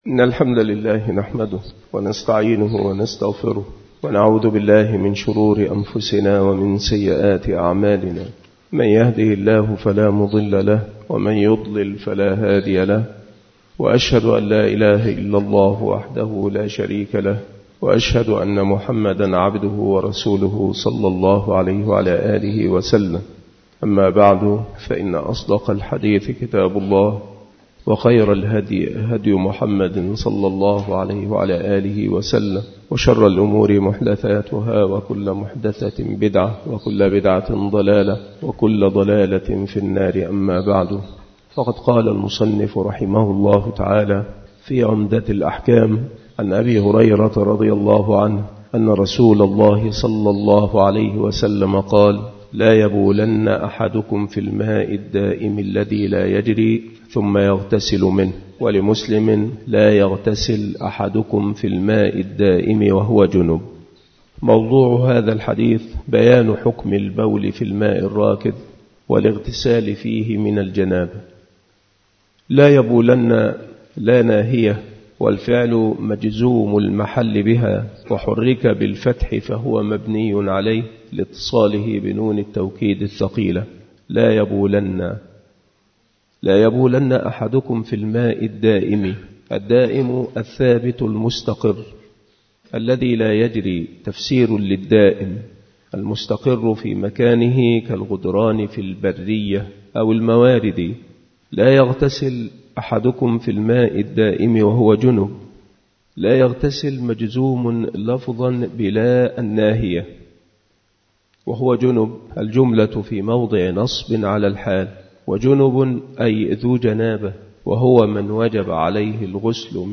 المحاضرة
مكان إلقاء هذه المحاضرة بالمسجد الشرقي بسبك الأحد - أشمون - محافظة المنوفية - مصر